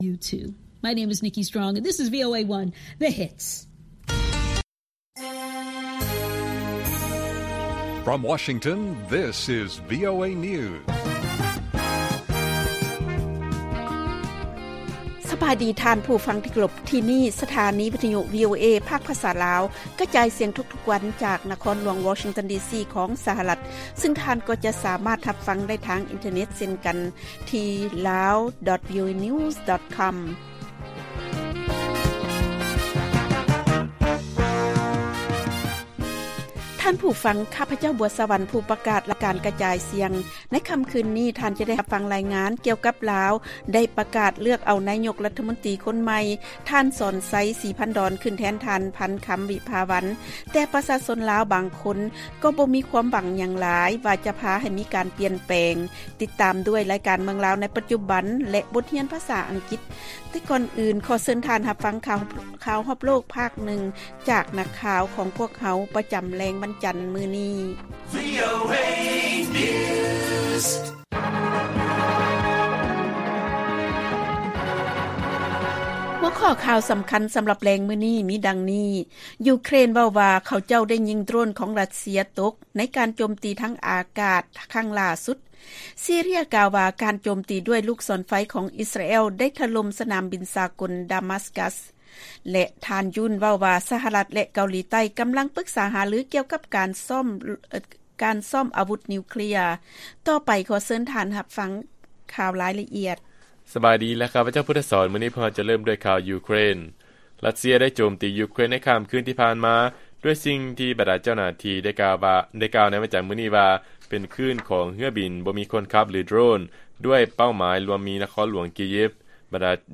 ລາຍການກະຈາຍສຽງຂອງວີໂອເອ ລາວ: ຢູເຄຣນ ເວົ້າວ່າເຂົາເຈົ້າໄດ້ຍິງໂດຣນຂອງ ຣັດເຊຍ ຕົກໃນການໂຈມຕີທາງອາກາດຄັ້ງຫຼ້າສຸດ